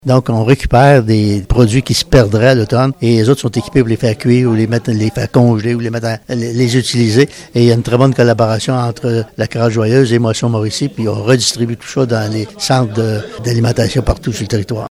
Un projet qui est aussi très important pour des organismes en aide alimentaire comme Moisson-Mauricie Centre-du-Québec qui peut s’approvisionner en surplus de production ou en produits difformes, comme l’explique le maire de Saint-Léonard-d’Aston et ancien président de Moisson, Jean-Guy Doucet.